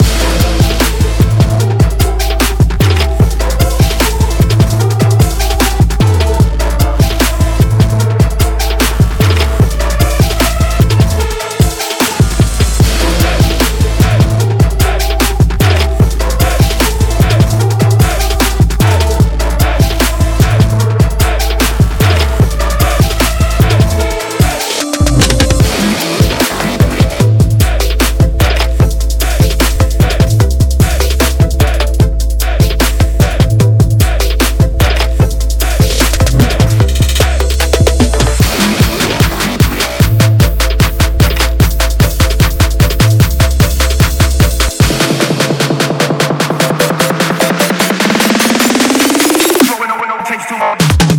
EDM/TRAPの各ループ素材はリズム音源トラックをメインに、各種TRAP系のシンセ音源などでミックス。